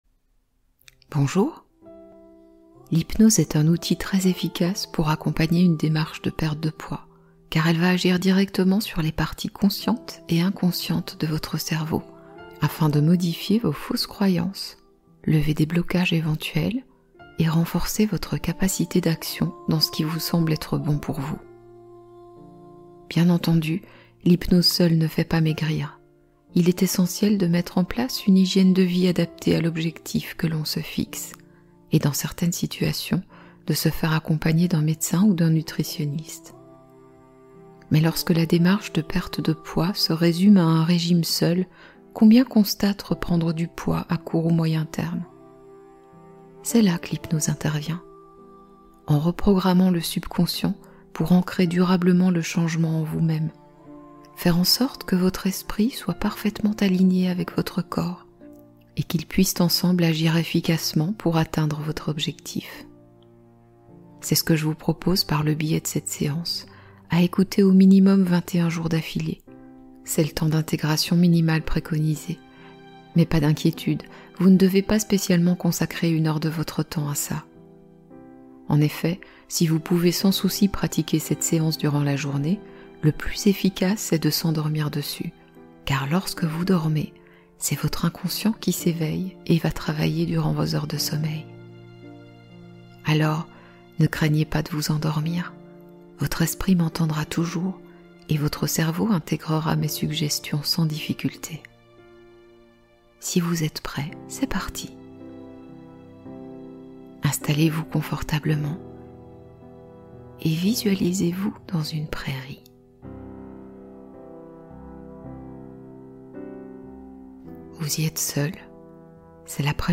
Sommeil guidé : apaisement progressif et sécurisant